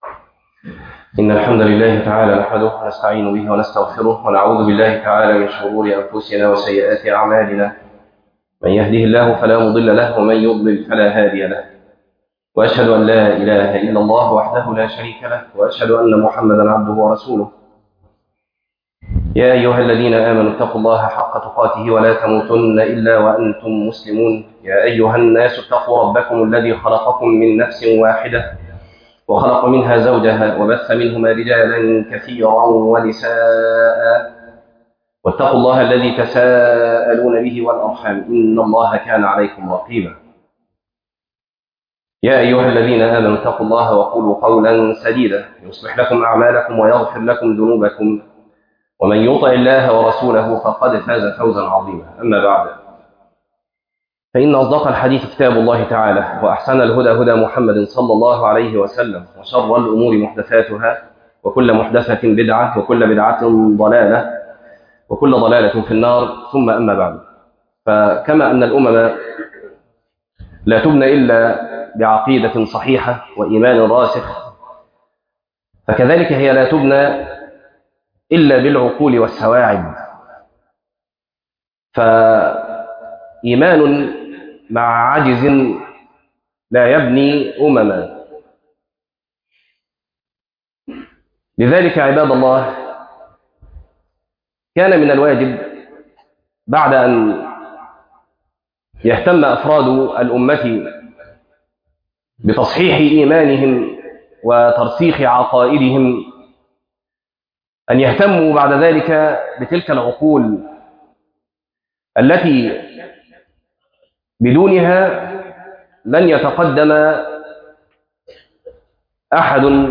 العقــل - خطبة